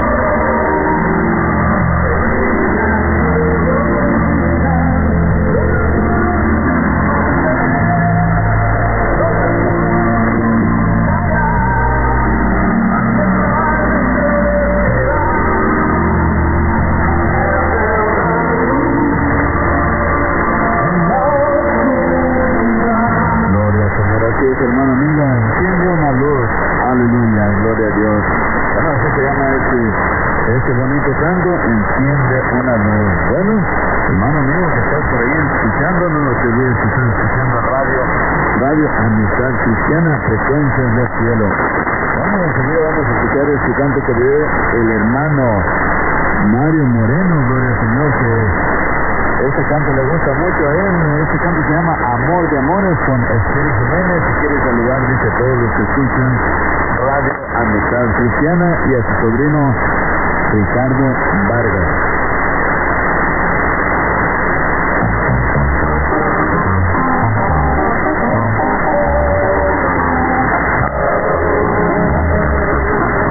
ID: identification announcement